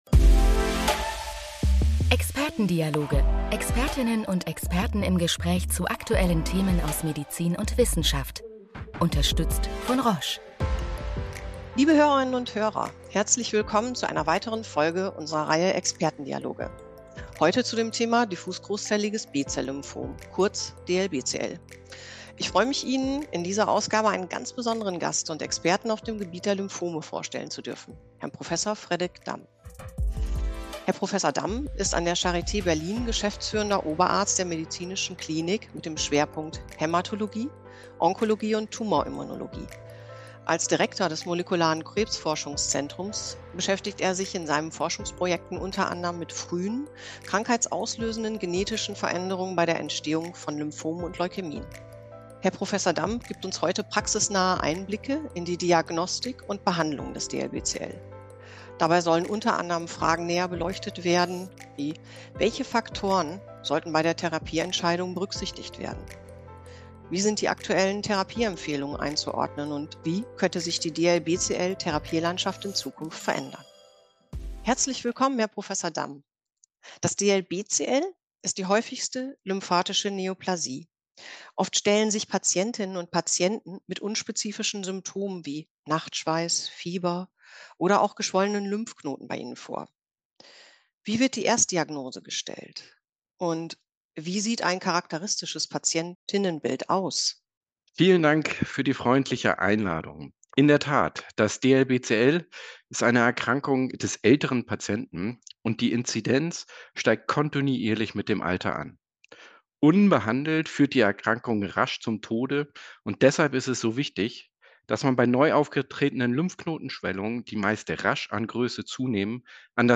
In diesem „Expertendialog“ sprechen wir mit Prof.